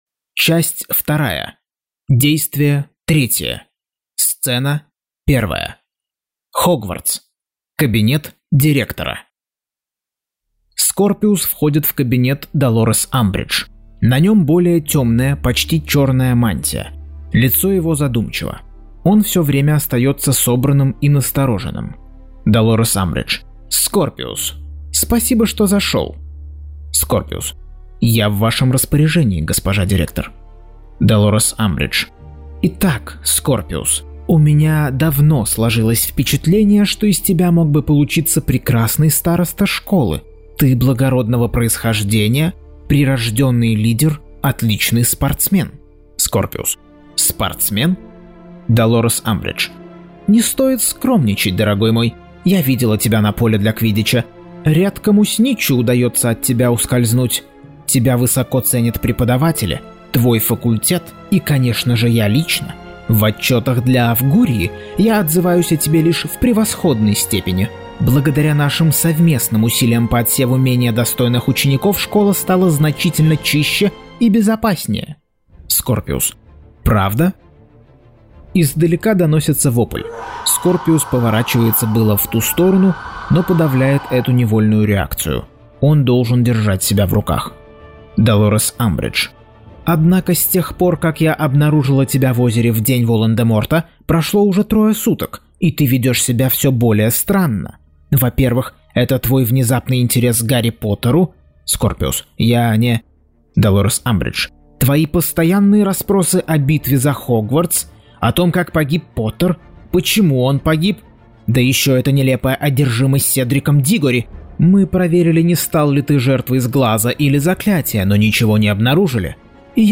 Аудиокнига Гарри Поттер и проклятое дитя. Часть 33.